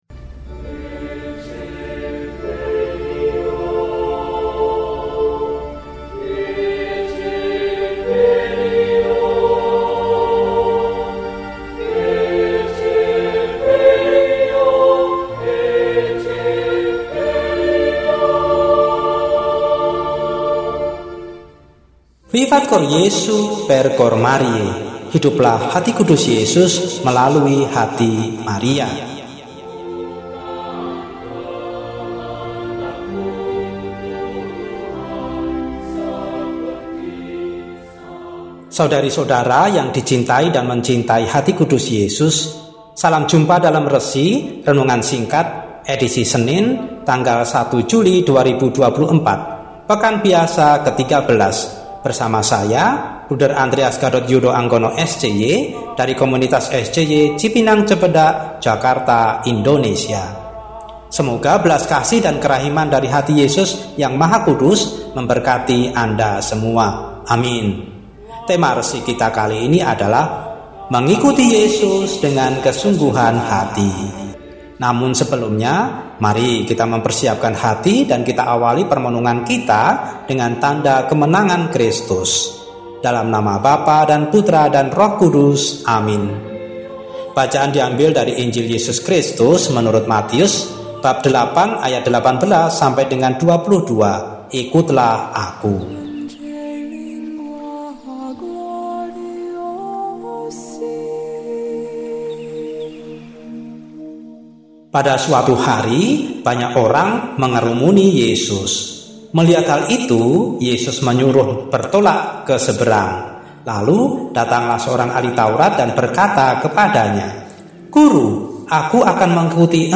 Senin, 01 Juli 2024 – Hari Biasa Pekan XIII – RESI (Renungan Singkat) DEHONIAN